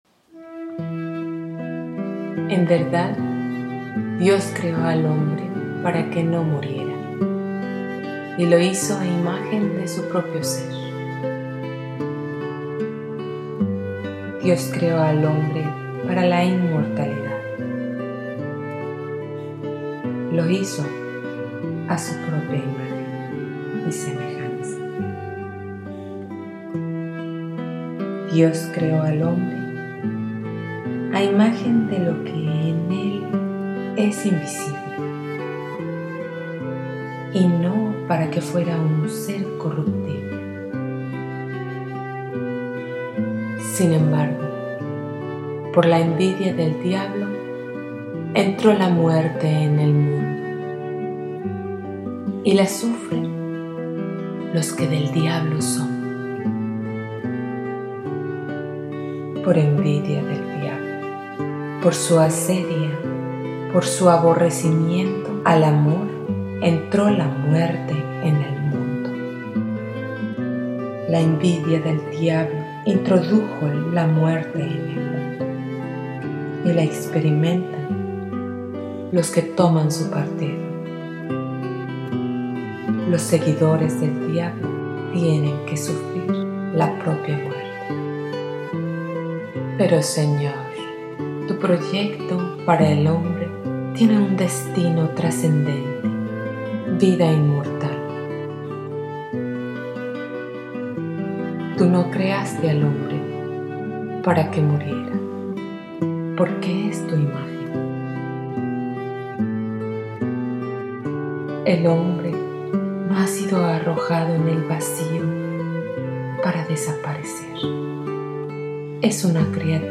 LECTURA MEDITADA